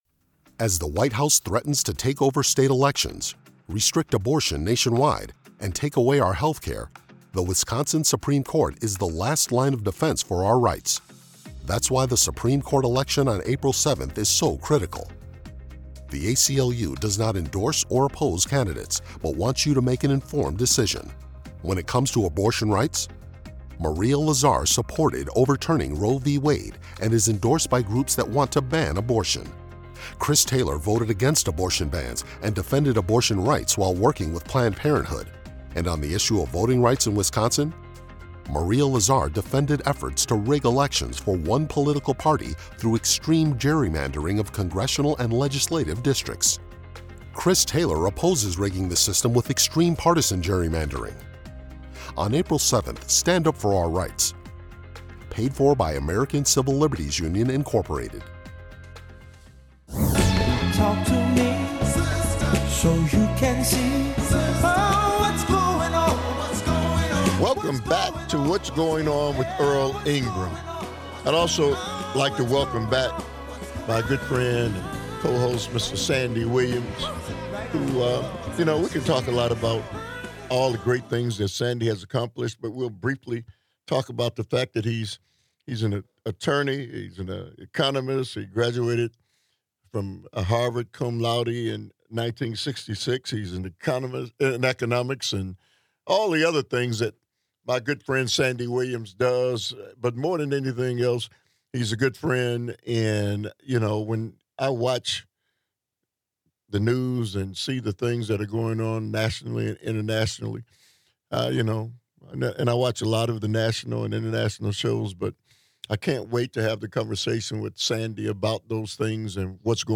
From the lessons of the Nuremberg Trials to the growing tension around executive power, civil liberties, tariffs, and public trust, this conversation asks what kind of country we’re becoming and who pays the price when norms break down. This is a direct, thought-provoking discussion about power, accountability, and why everyday people can’t afford to tune out.